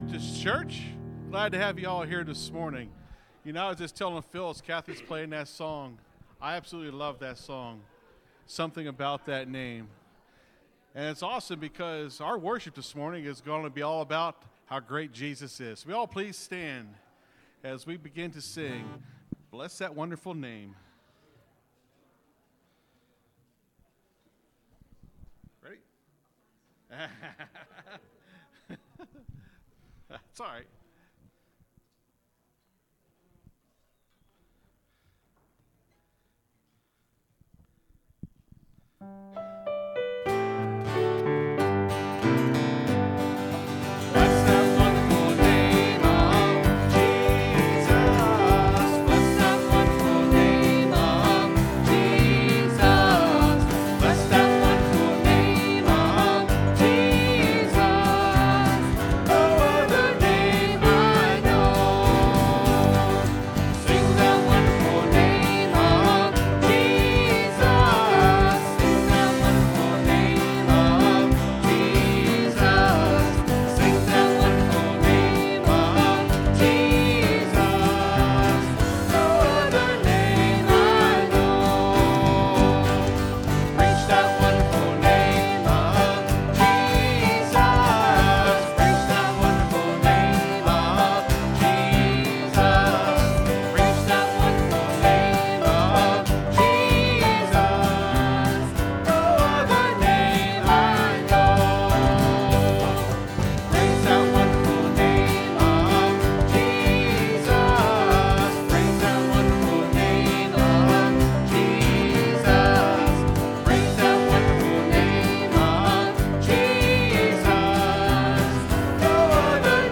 (Sermon starts at 26:30 in the recording).